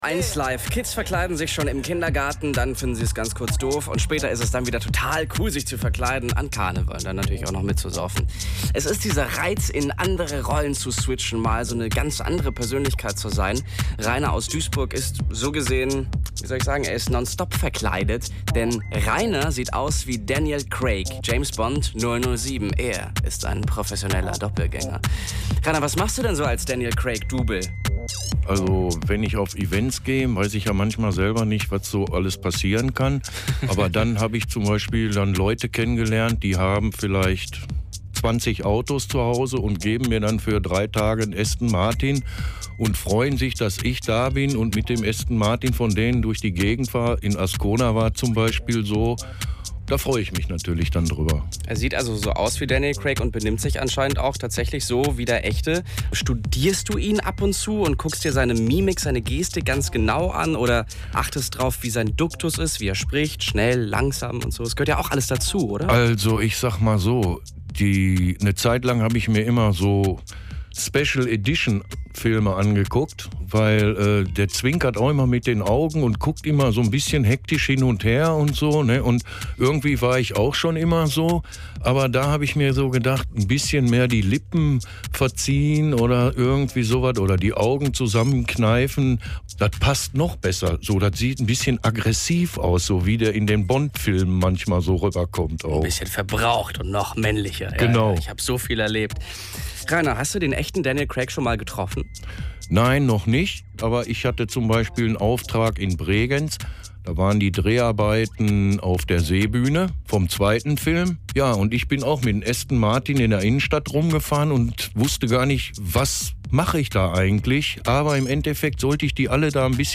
Radio
1LIVE - Interview